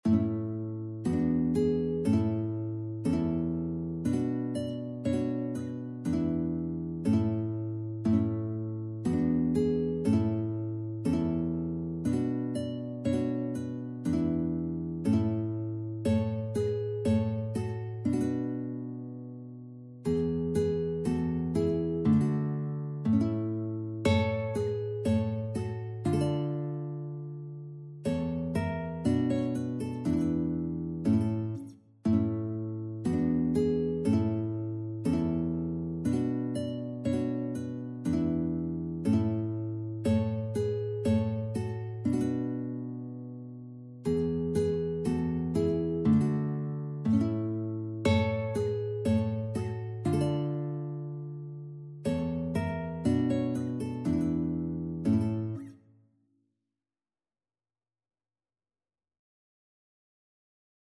Tradycyjna polska kolęda "Gdy Śliczna Panna", w tonacji A-dur opracowana w technice fingerstyle na gitarę.
Instrument Gitara
Gatunek Kolęda